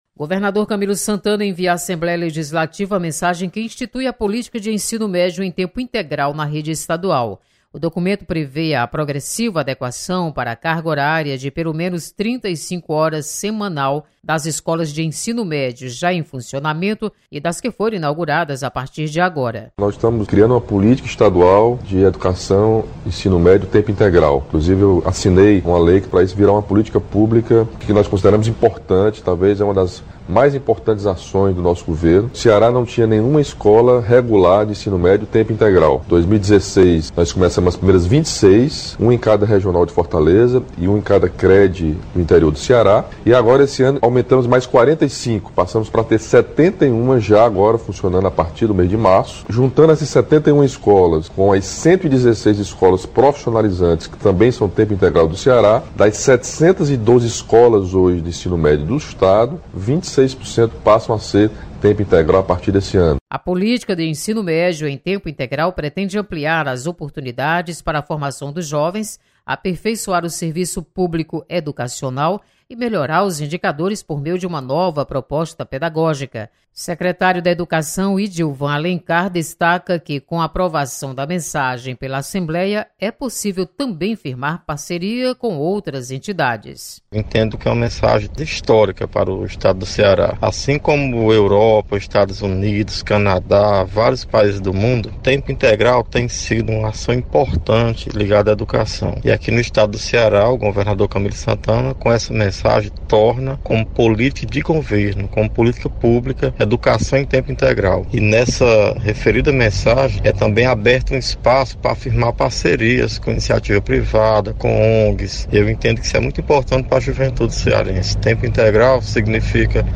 Mensagem sobre política do Ensino Médio estadual tramita na Assembleia Legislativa. Repórter